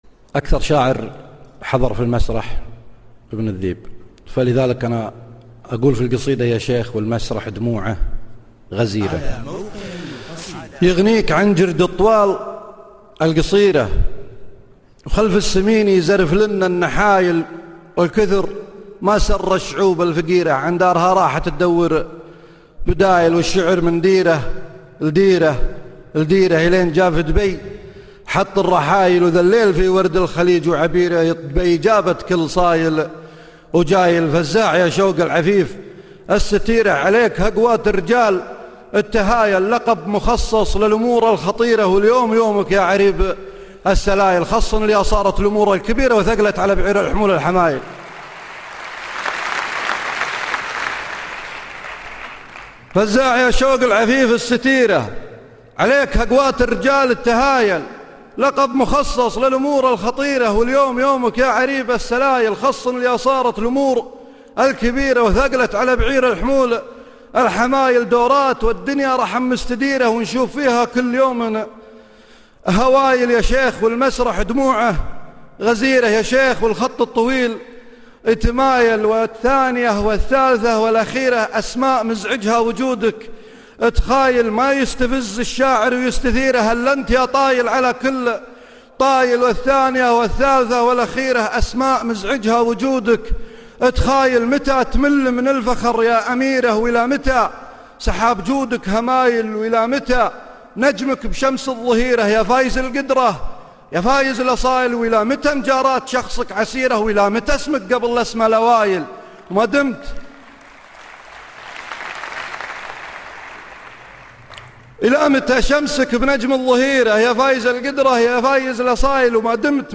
عريب السلايل (ملتقى دبي 2012)   0/ 5